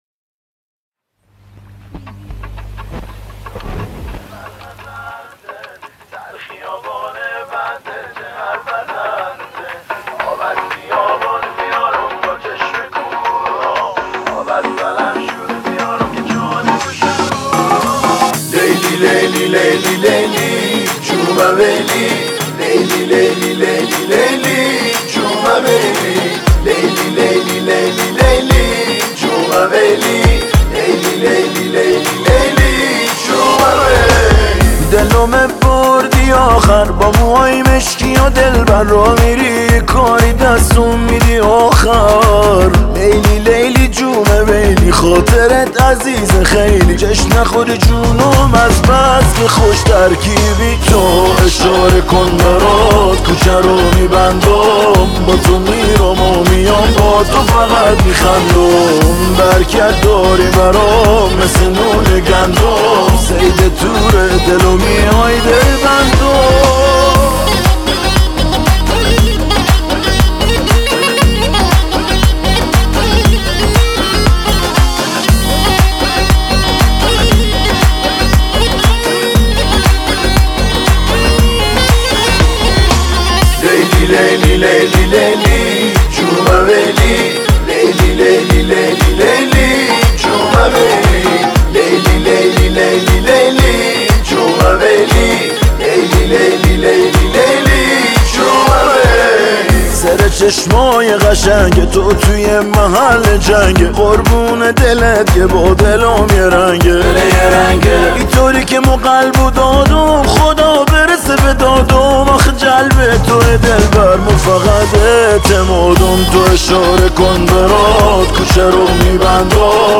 پاپ شاد رقص عاشقانه